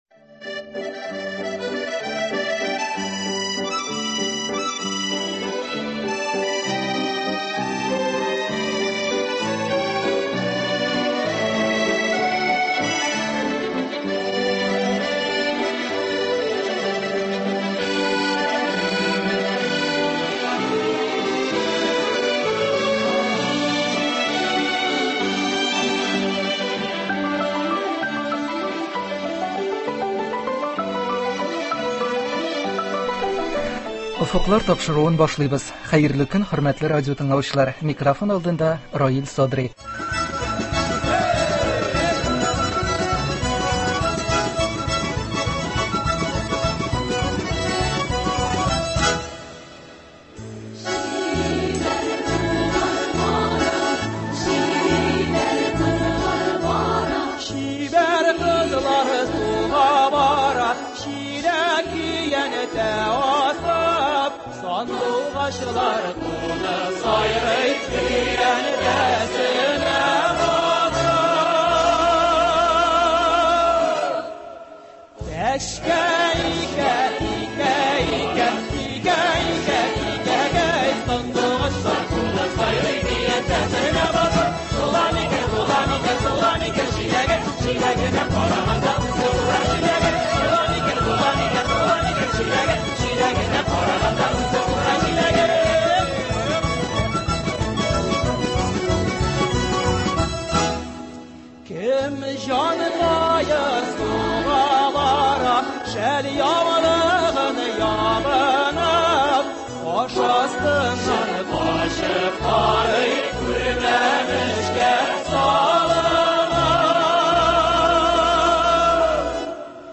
Татарстан Республикасы дәүләт җыр һәм бию ансамбленең 85 еллыгына багышланган әңгәмә.